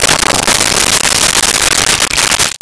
Pour Coffee 02
Pour Coffee 02.wav